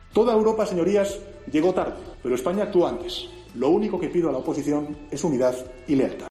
Una de las intervenciones de Pedro Sánchez hoy en el Congreso